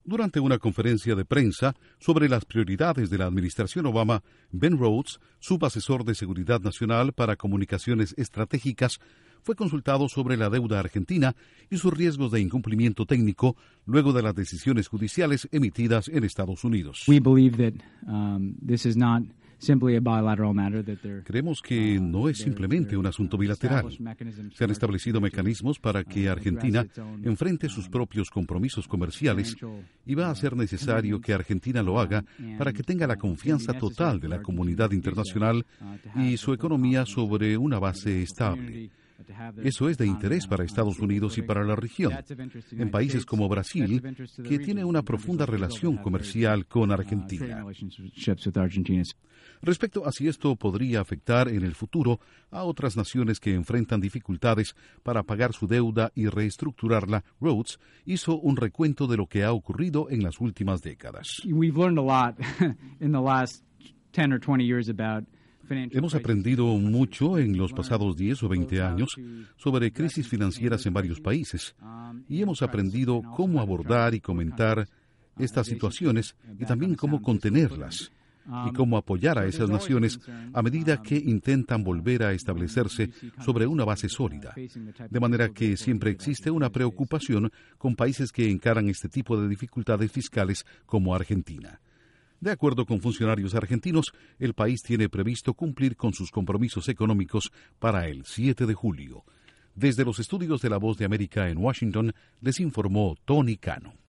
Intro: Un importante asesor de la Casa Blanca dice que existen mecanismos para que el gobierno argentino cumpla con sus compromisos financieros que se vencen en los próximos días. Informa desde la Voz de América en Washington